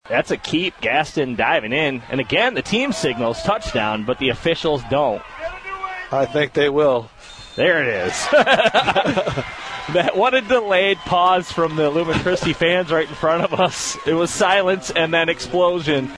Audio from WKHM’s radio broadcast